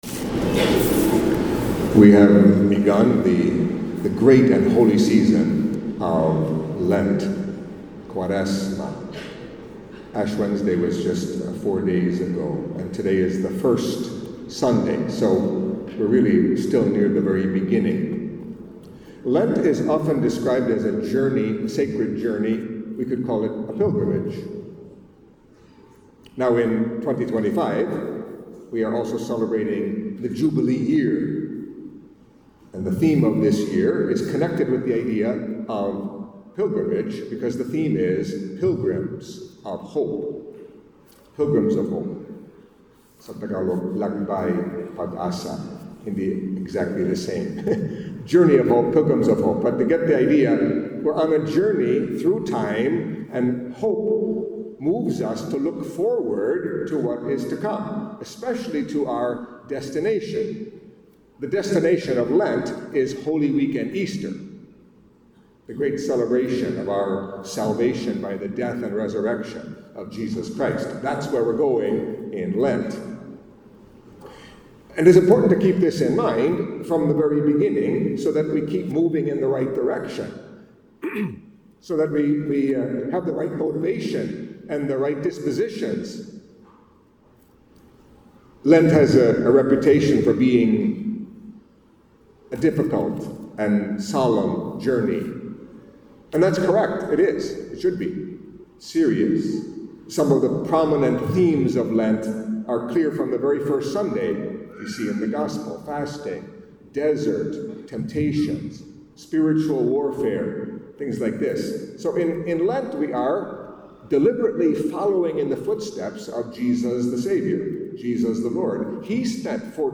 Catholic Mass homily for First Sunday of Lent